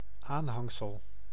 Ääntäminen
Synonyymit vermiform appendix Ääntäminen US UK : IPA : /əˈpɛn.dɪks/ US : IPA : /əˈpɛn.dɪks/ Lyhenteet ja supistumat (laki) app.